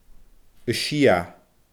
a sia /ə ʃia/